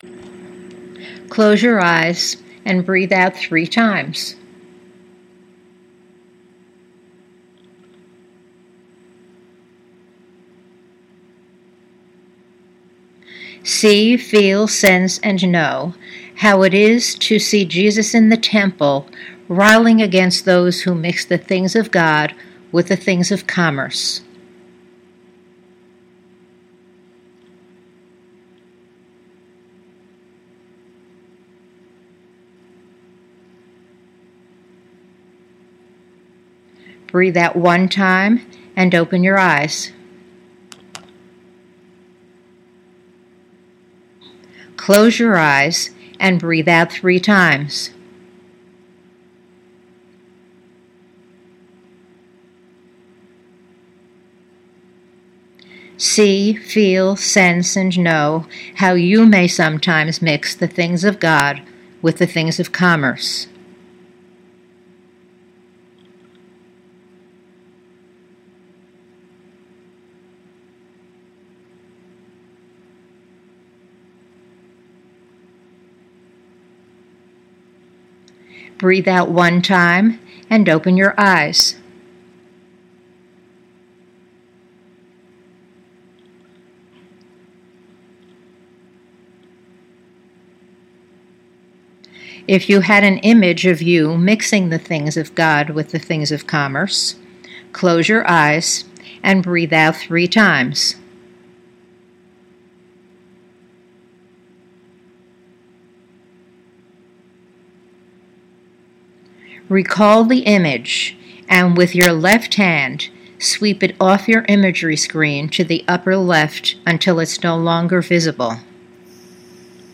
Note:  The three exercises in this group follow one another on the tape with just a brief interval between and should be done together.